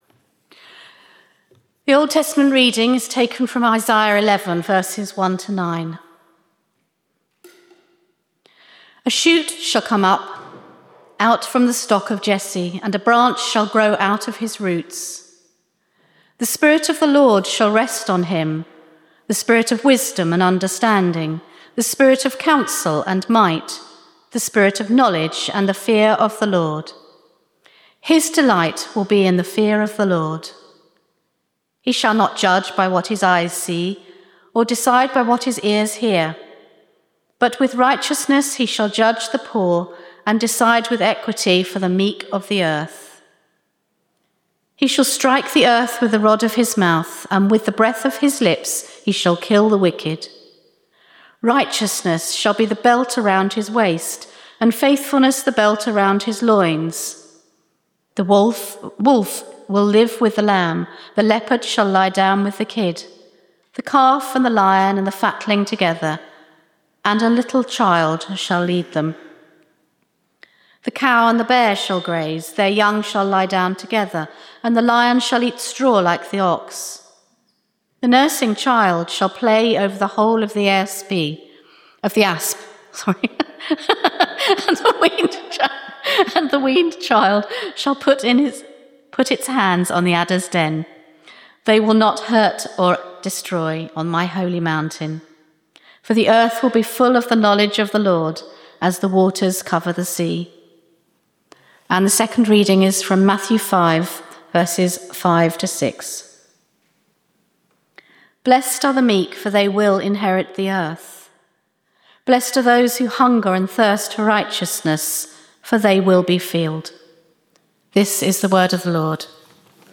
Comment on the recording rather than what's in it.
Location: St Mary’s, Slaugham Date Service Type: Communion